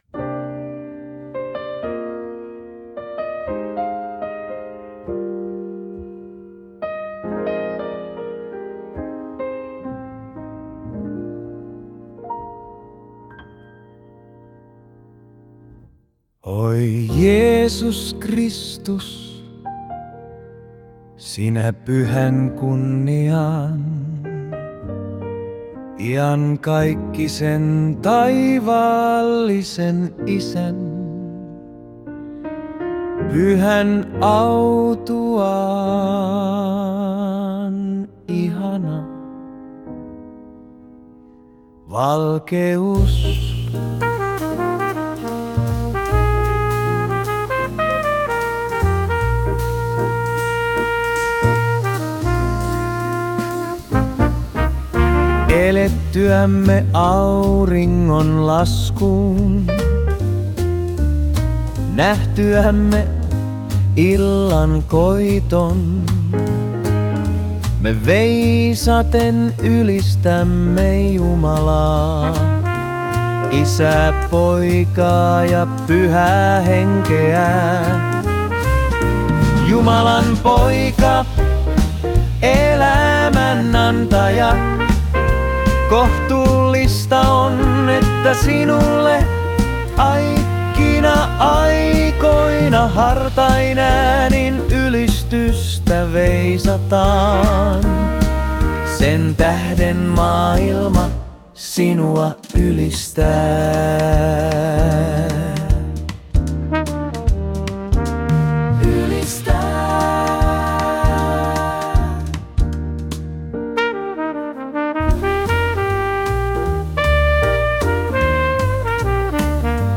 Ehtooveisu jazz-tyyliin